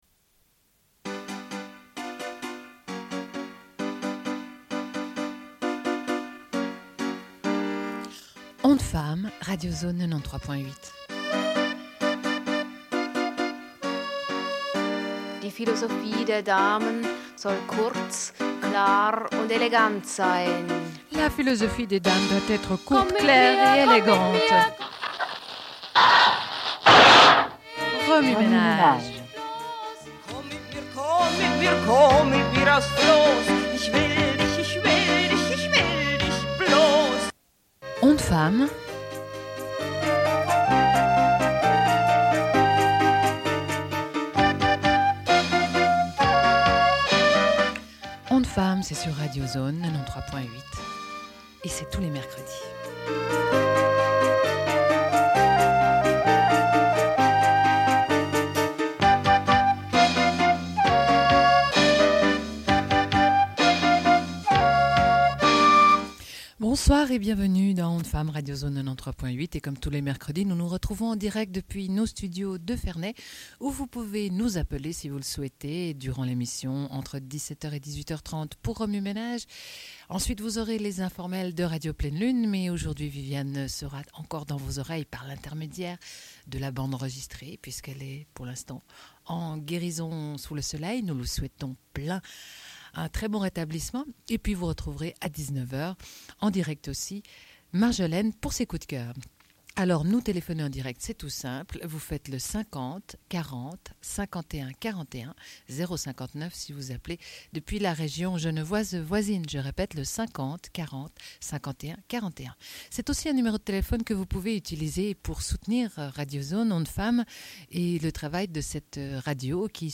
Une cassette audio, face A31:32
00:09:29 - Récit d'un 8 mars à Alger par une genevoise. - 00:27:13